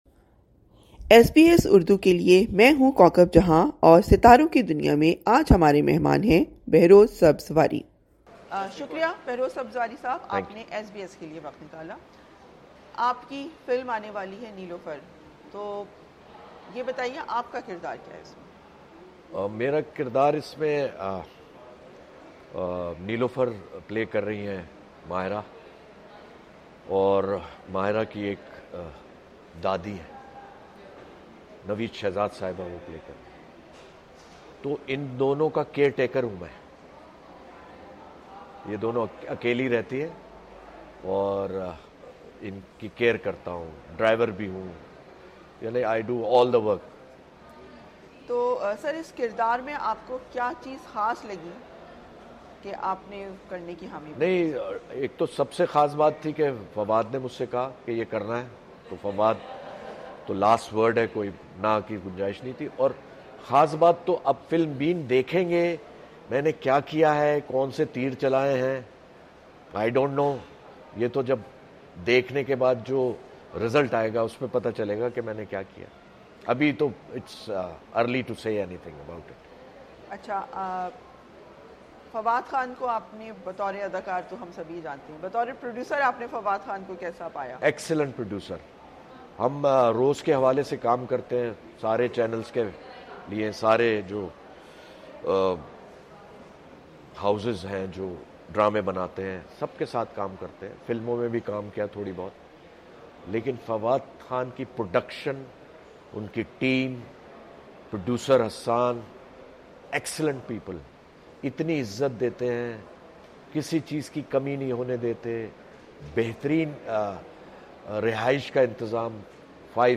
ایس بی ایس کے ساتھ خصوصی انٹرویو میں بہروز سبزواری نے اپنی نئی فلم نیلوفر میں اپنے کردار، فواد خان اور ماہرہ خان کے ساتھ کام کرنے کے تجربے اور پاکستانی فلموں کے موضوعات اور ان کے بزنس پر بات کی۔